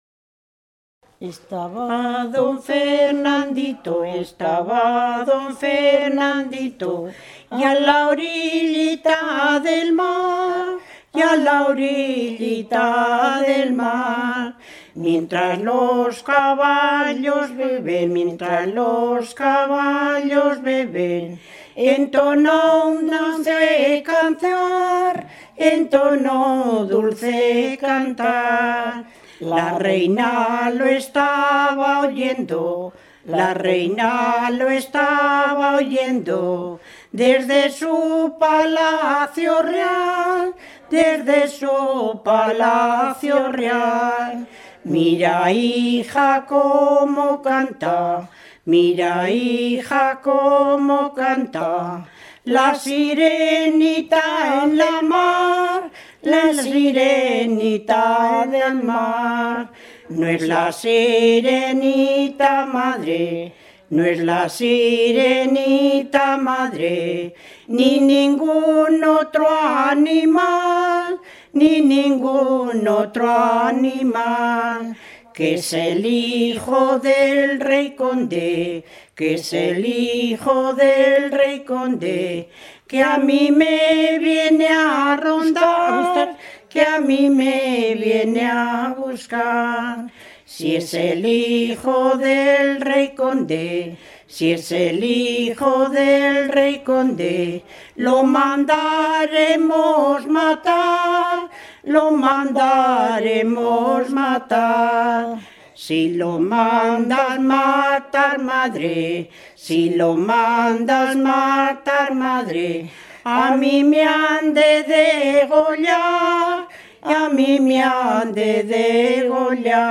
Clasificación: Romancero
Todas son cantadas y con gran profusión de melodías. Las propias informantes son consciente de estar cantando algo realmente antiguo.